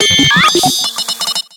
Grito de Porygon-Z.ogg
Grito_de_Porygon-Z.ogg